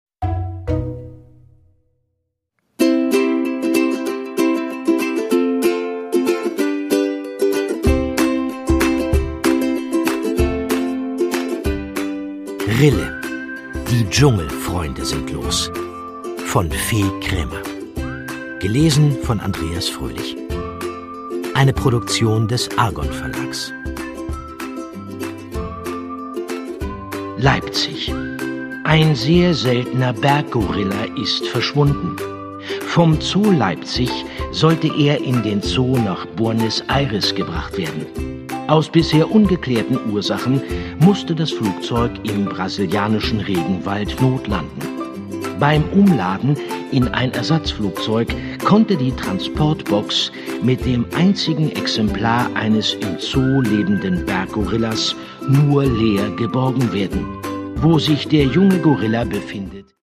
Produkttyp: Hörbuch-Download
Gelesen von: Andreas Fröhlich